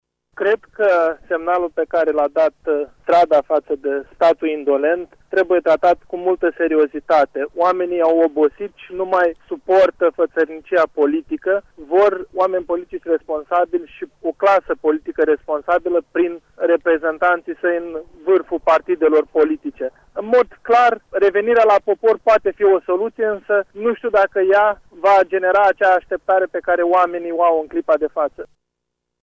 Nici vicepreşedintele Mişcării Populare, Eugen Tomac, nu este în favoarea alegerilor anticipate: